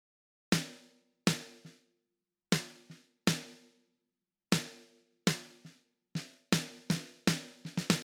ポップスなどに向いた、ハイファイな音
• 6kHzを+6dB
スネアのみ
中低域を大きく削る事により、ハイファイっぽい締まった音になりました。
高域を強調する事で、スナッピーの響きがだいぶ前に出てきています。